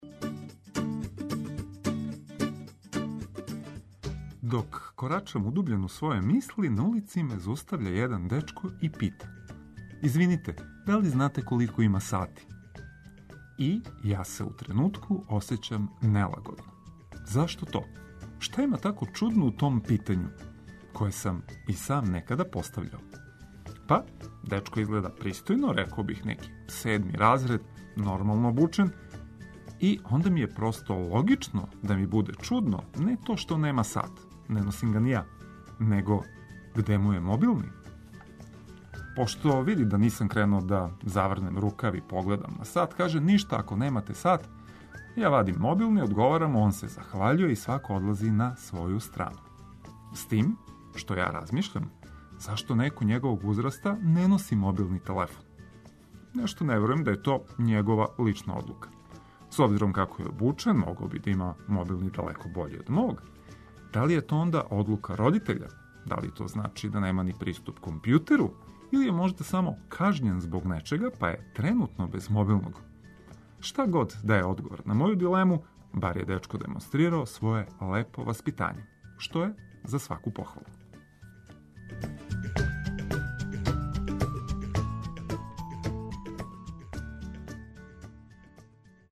Уз хитове и важне информације дочекајте први ледени дан ове јесени.